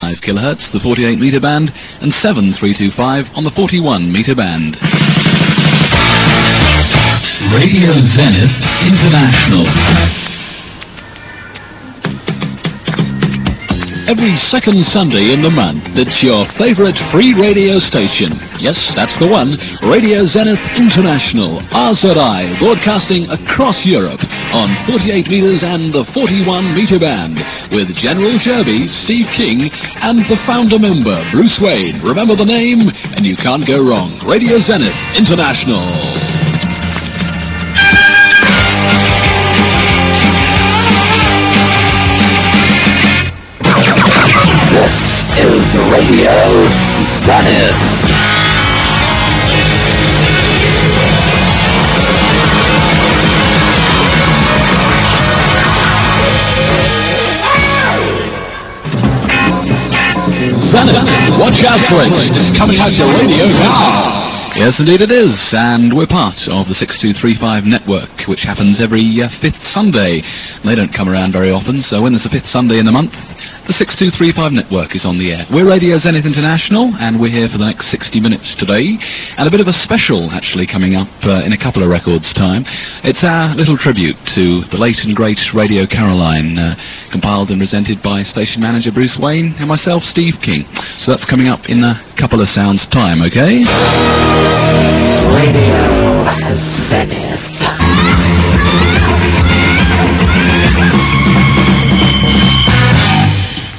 Their 48 and 41 metre bank rock service was broadcast BI-weekly on short wave until the station 'joined' the 6235 Network. Notable for its unique one off stereo transmission on short wave, where the stereo separation was excellent due to the left and right channels being broadcast on entirely different frequencies(!)